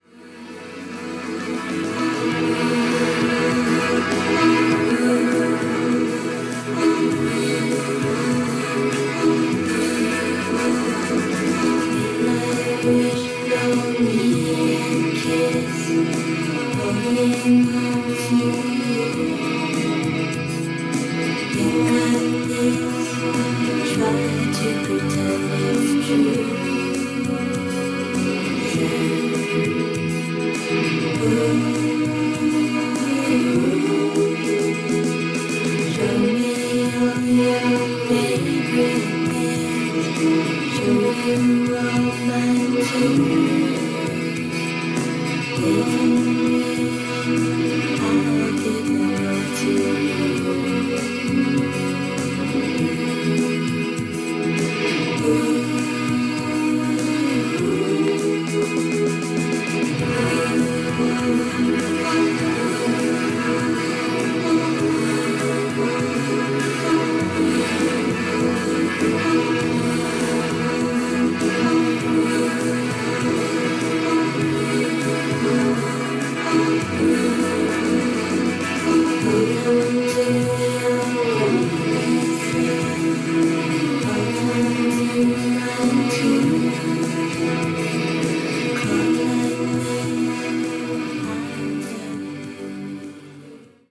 しっかりノイジーなんだけど、不思議とハマる（笑）